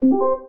Dock.wav